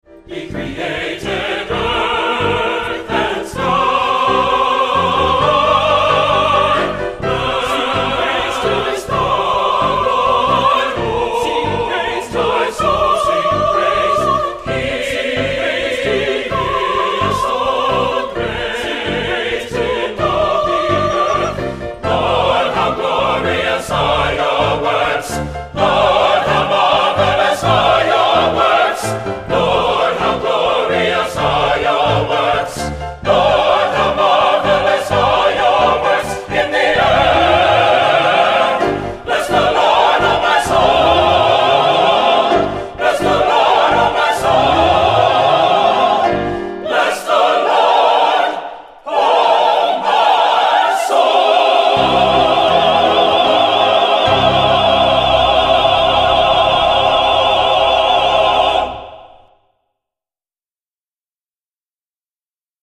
Accompaniment:      With Piano
Music Category:      Christian